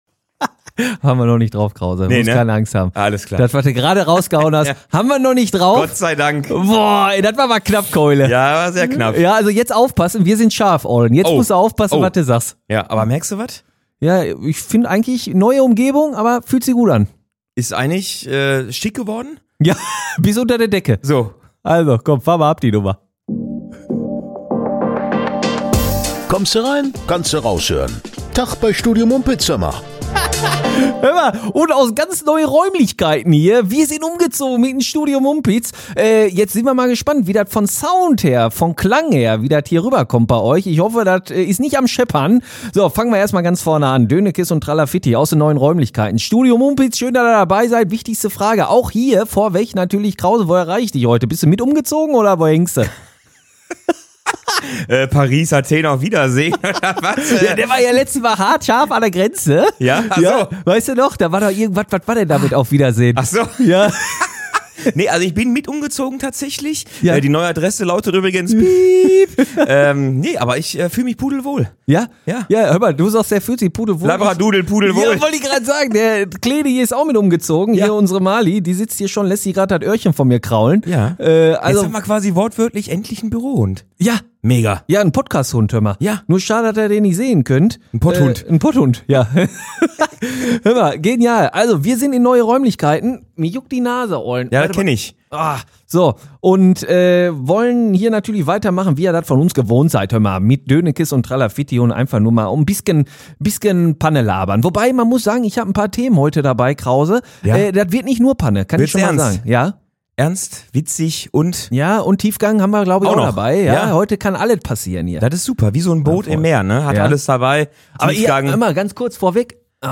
Neuet Studio, bekannter Humor: Ein Fuß kaputt, eine Stimme belegt, ne humpelnde Taube, die U35-Sozial-Linie, Diskussion um ne deutsche Ärztin, Ruhrpott-Deutsch für besseret Ruhrpott-Deutsch, töffte Fragen im Ruhrpott-Quiz und Graffiti-Züge.